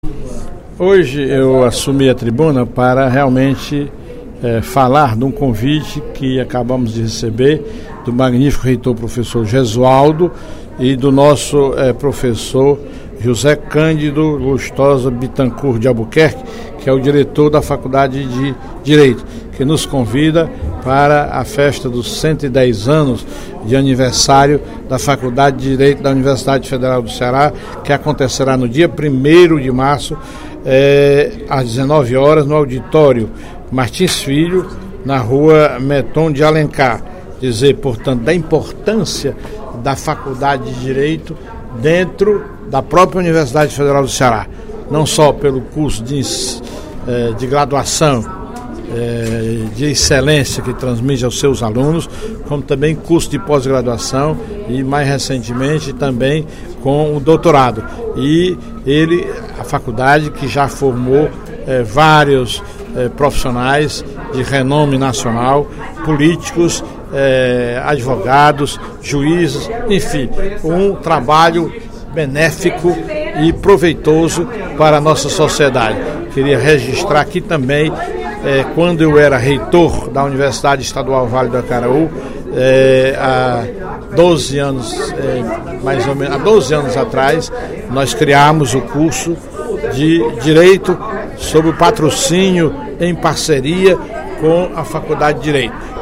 A história da centenária Faculdade de Direito da Universidade Federal do Ceará (UFC) foi relembrada pelo deputado Professor Teodoro (PSD), durante pronunciamento no primeiro expediente da sessão plenária desta quarta-feira (27/02).